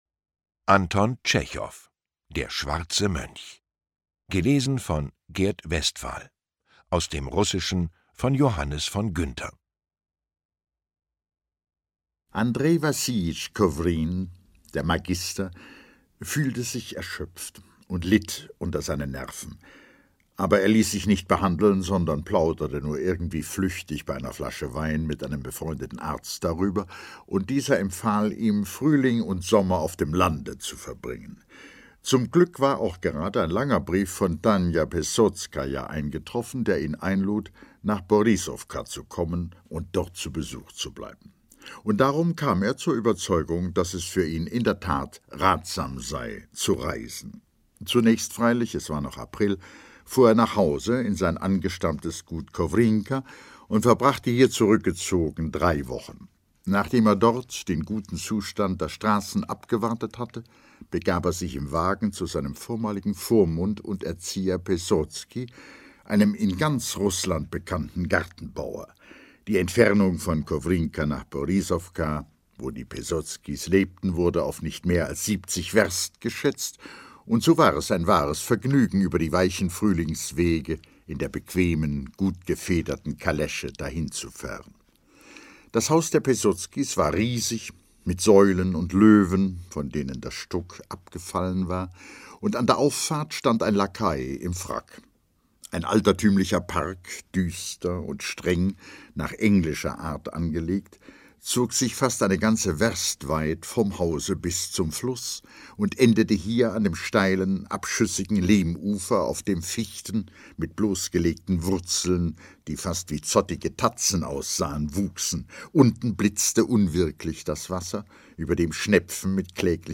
Lesung mit Gert Westphal (1 mp3-CD)
Gert Westphal (Sprecher)